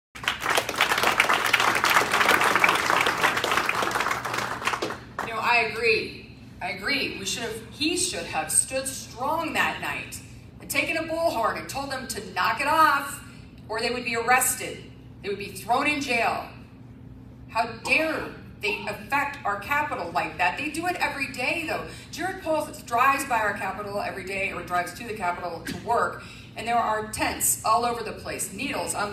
Last week the two remaining Republican candidates for Governor took part in their first post-assembly debate.